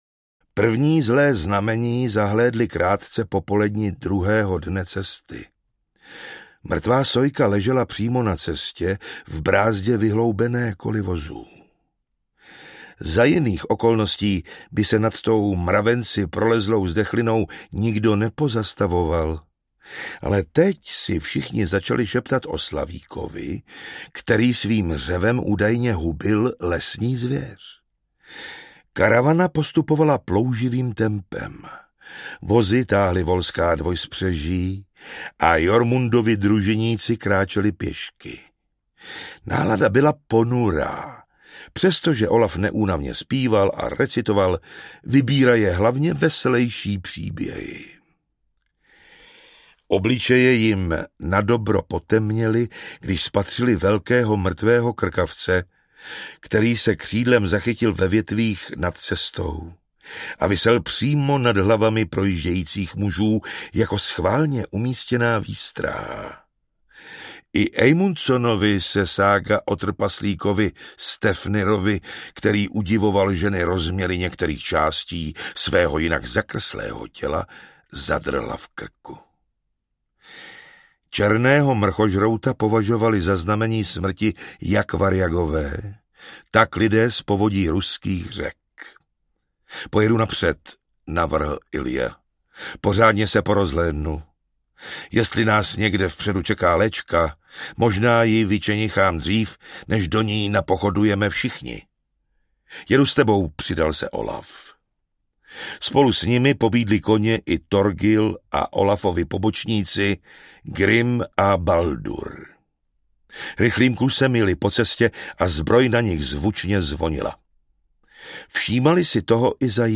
Doupě řvoucí smrti audiokniha
Ukázka z knihy
Vyrobilo studio Soundguru.